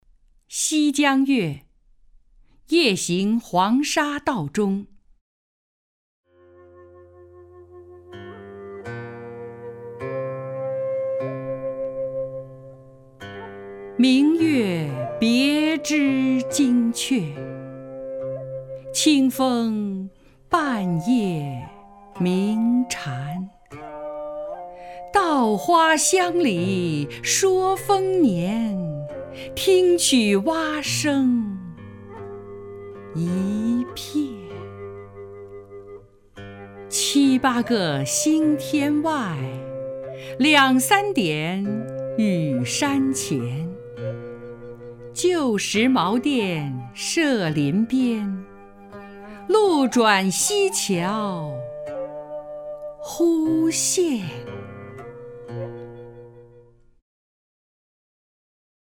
首页 视听 名家朗诵欣赏 张筠英
张筠英朗诵：《西江月·夜行黄沙道中》(（南宋）辛弃疾)